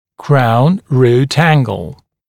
[kraun ruːt ‘æŋgl][краун ру:т ‘энгл]угол между коронкой и корнем